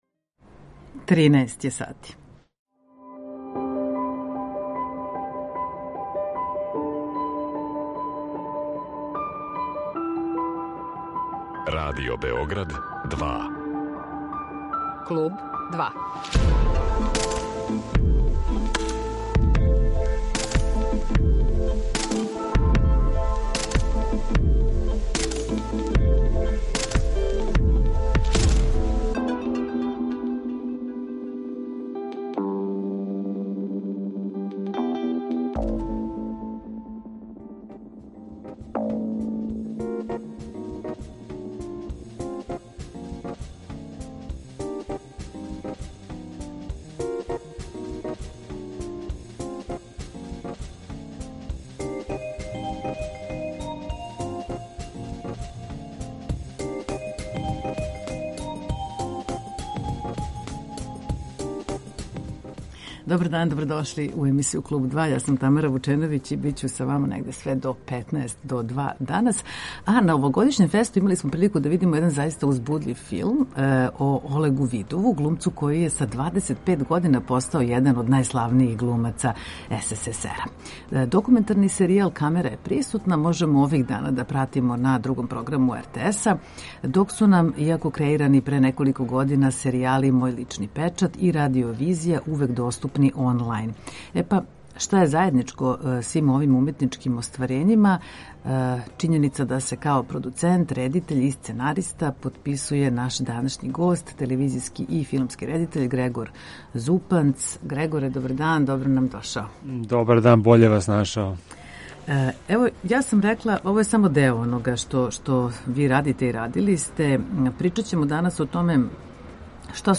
Са њим разговарамо о иновативним визуелним садржајима на којима ради и о томе како се уопште филм ствара онлајн, о животу и раду у Лос Анђелесу, „пробијању" у индустрији филма у близини Холивуда и сарадњи на пројекту Илона Маска, о продукцији поткаста и многим другим занимљивим темама.